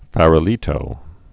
(fărə-lētō, fär-)